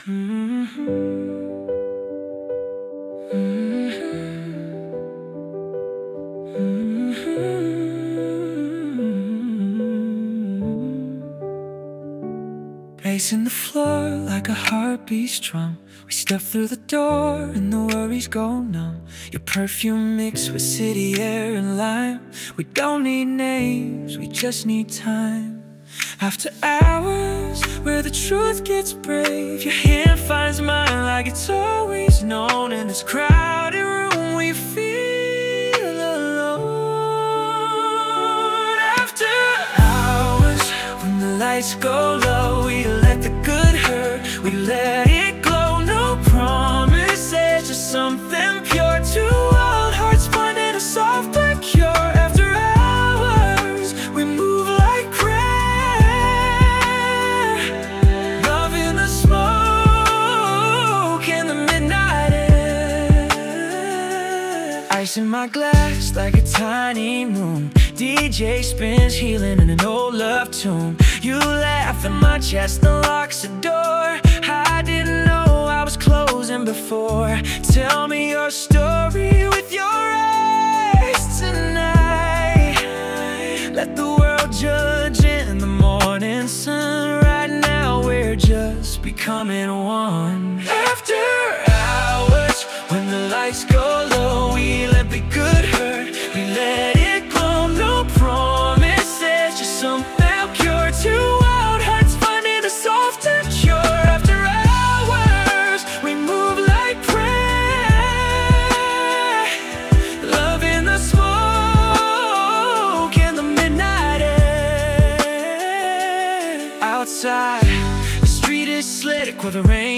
Pop 2026 Non-Explicit